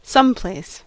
Ääntäminen
Synonyymit (amerikanenglanti) somewhere Ääntäminen US : IPA : [ˈsʌm.ˌpleɪs] Haettu sana löytyi näillä lähdekielillä: englanti Käännöksiä ei löytynyt valitulle kohdekielelle.